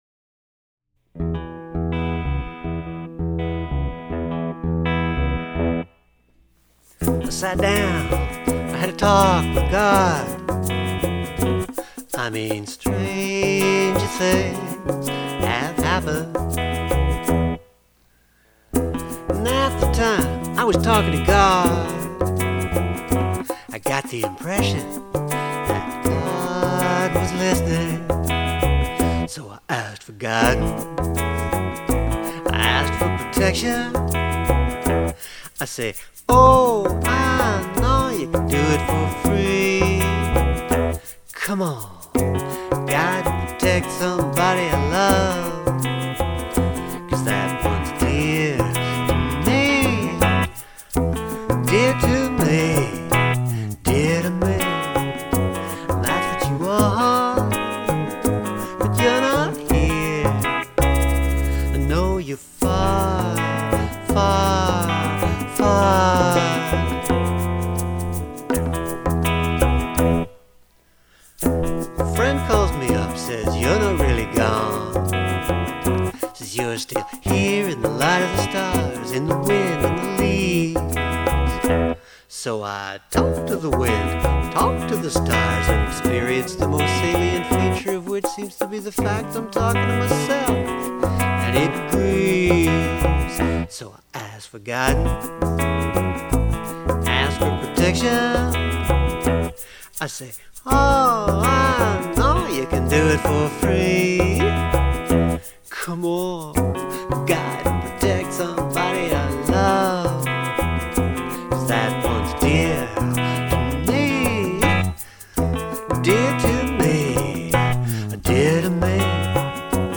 Pretty much raw tracks here. Haven't started mixing it.